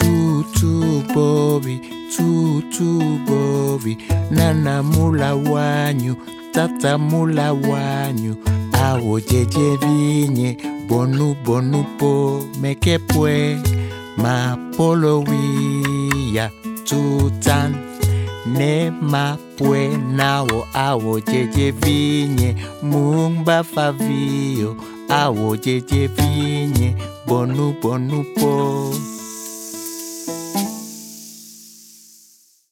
Orchestrations et direction des interprètes pour un rendu sonore optimal.
MES-PLUS-BELLES-COMPTINES-AFRICAINES.mp3